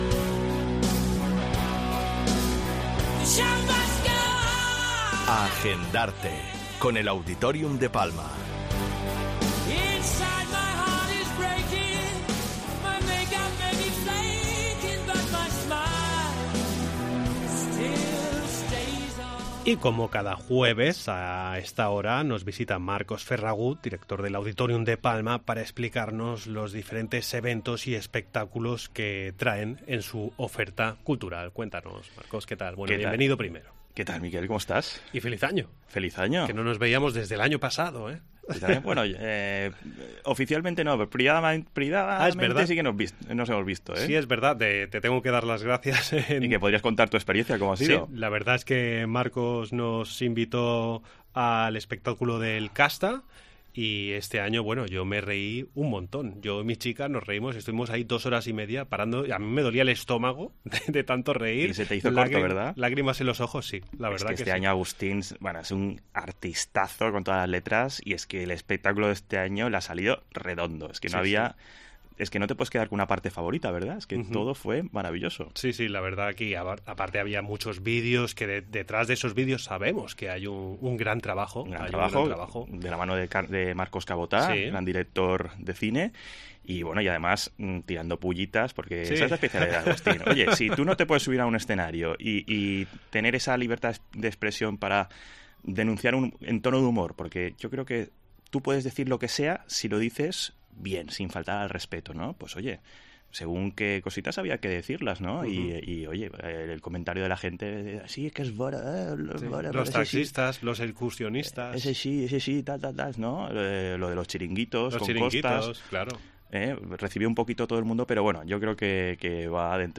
Entrevista en 'La Mañana en COPE Más Mallorca', jueves 20 de enero de 2023.